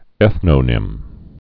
(ĕthnō-nĭm)